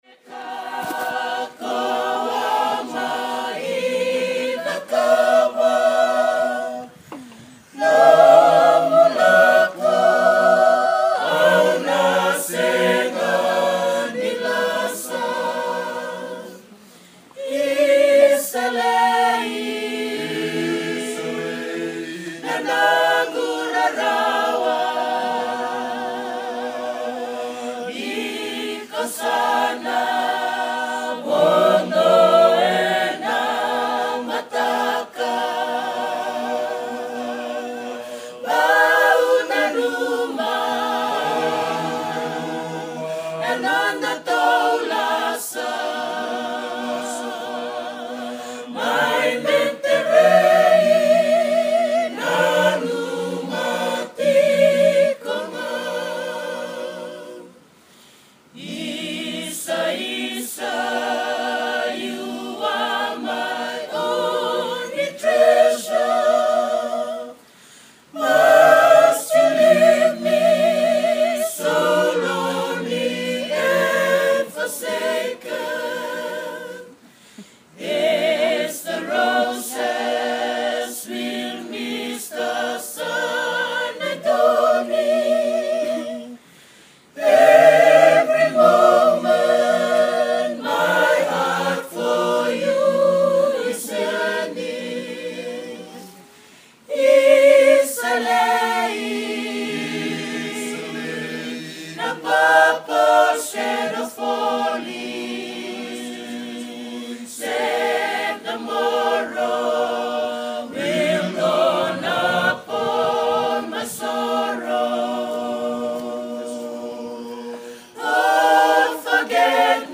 Performed and recorder at the beach of the Mantaray resort.
Recorded on September 16. 2014 with ordinary IPhone7.